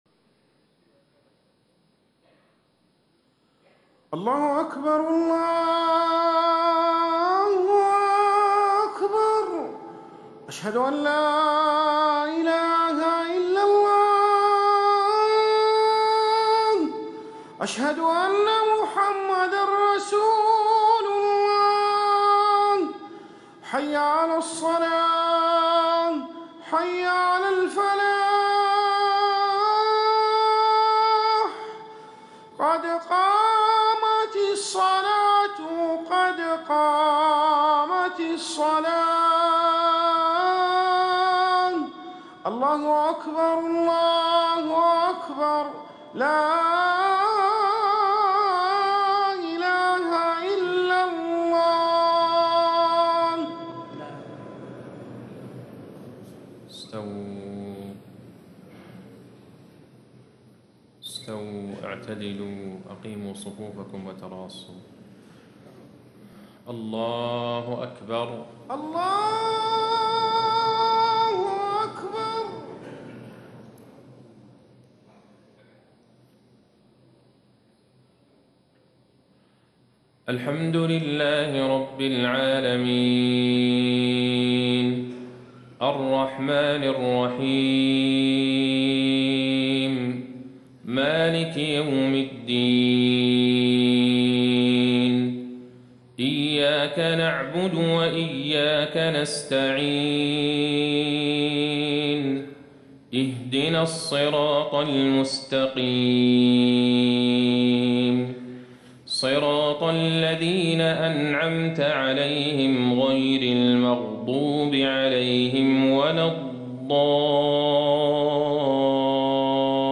صلاة الفجر 1 رمضان 1437هـ من سورة البقرة 183-187 > 1437 🕌 > الفروض - تلاوات الحرمين